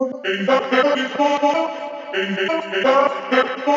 • chopped vocals ping pong house delayed (6) - C - 109.wav
chopped_vocals_ping_pong_house_delayed_(6)_-_C_-_109_MvI.wav